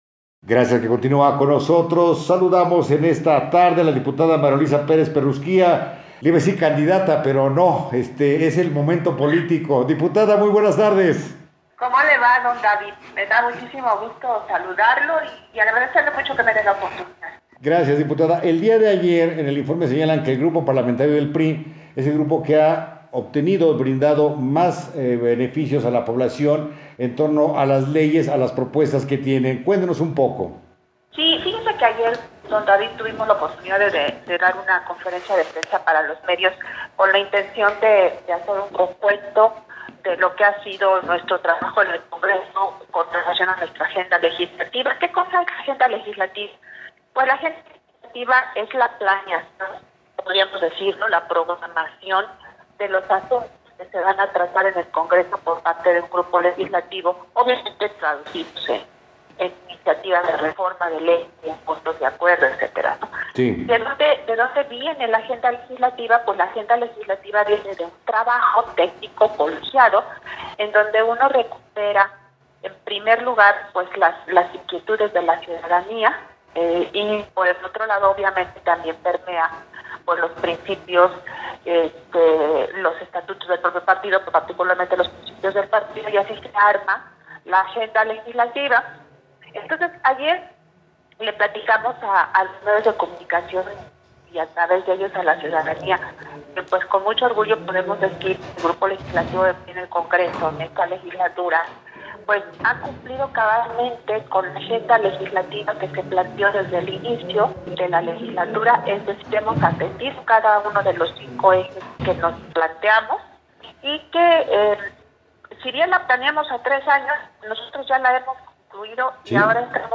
Entrevista
Pachuca, Hgo., 05 de marzo del 2021.- En entrevista, María Luisa Pérez Perusquía, coordinadora de la fracción parlamentaria del PRI en el Congreso del estado, refirió que el Grupo Legislativo del PRI, ha cumplido cabalmente con la Agenda legislativa que se planteó desde el inicio de la legislatura, que si bien, se planeó a tres años, ya se ha concluido, por lo que dijo, el grupo está listo para continuar trabajando con todo el compromiso, poniendo atención a la nueva realidad derivada de la pandemia.